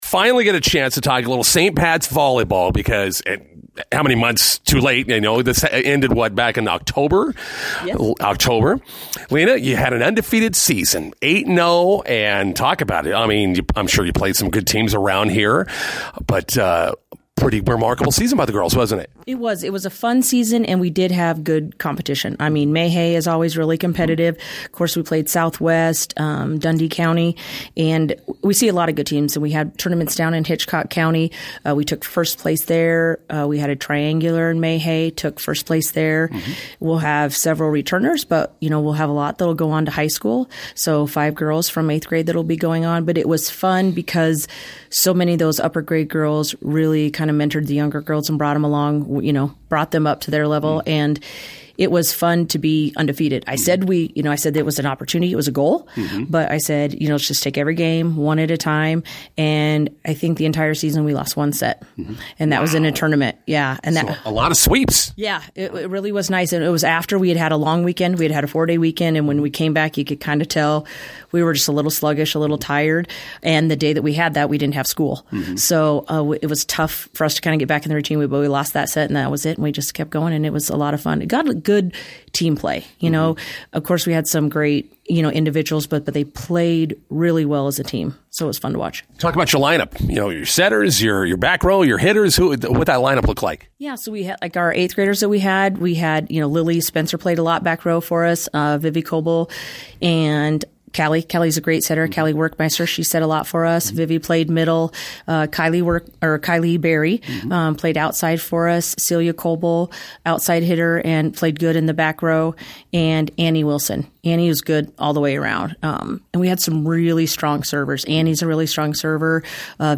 INTERVIEW: McCook St. Pat's volleyball wraps up an undefeated season.